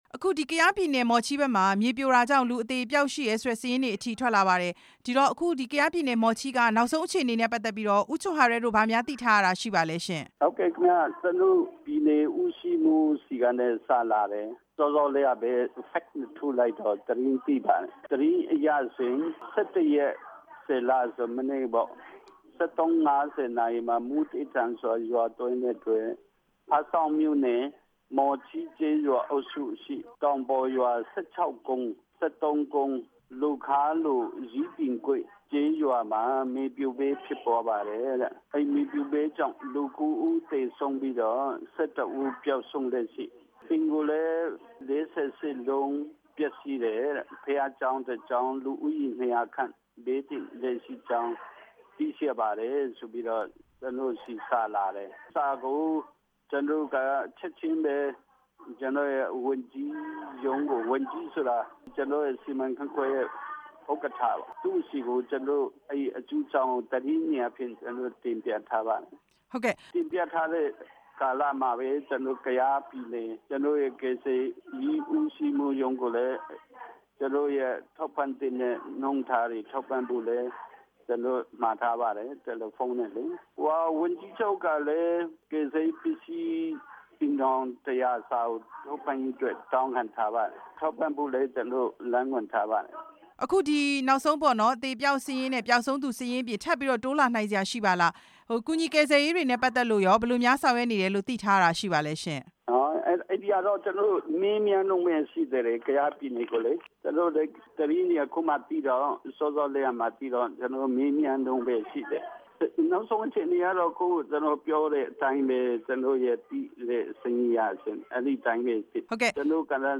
မော်ချီးဒေသမှာ မြေပြိုမှုရဲ့ နောက်ဆုံးအခြေအနေ မေးမြန်းချက်